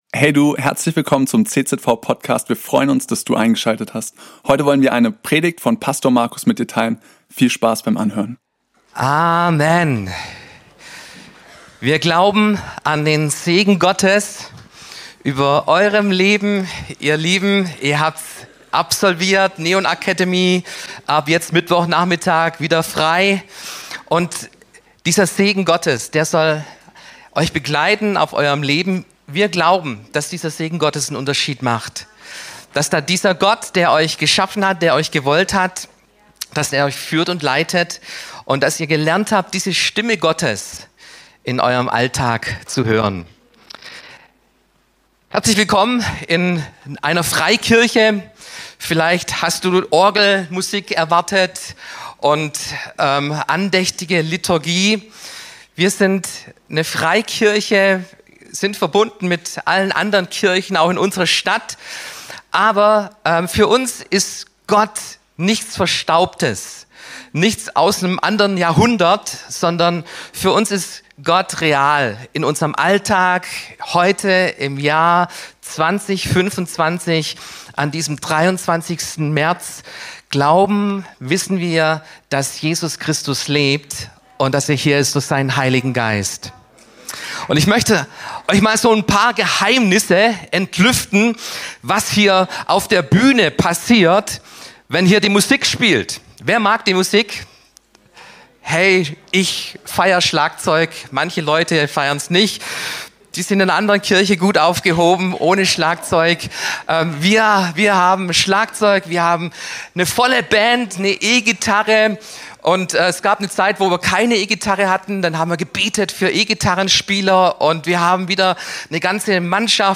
Mit diesem Podcast geben wir euch die Möglichkeit unsere Sonntagspredigten unter der Woche anzuhören.